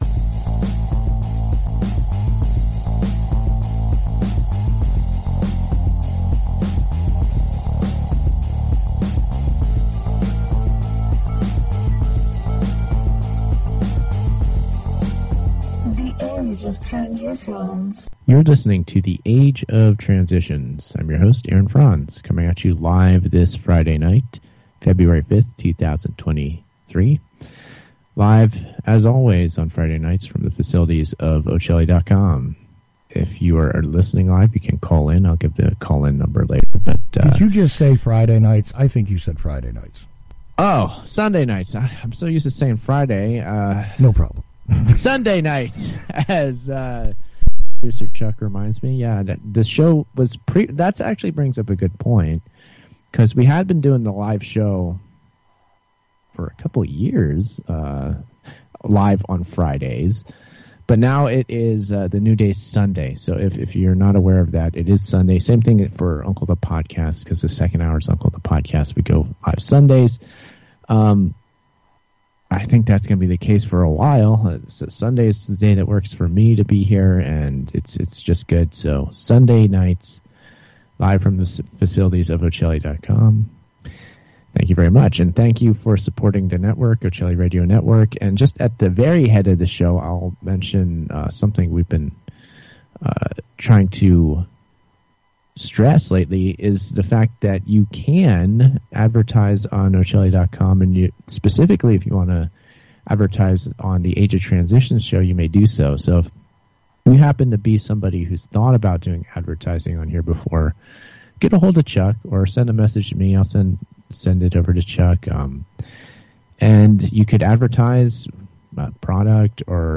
Fractional Forecasts Football Fantail - On The Age of Transitions and Uncle The Podcast LIVE 2-5-2023 - Callers make it work